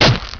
bite.wav